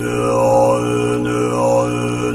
Monk Voice Low Chant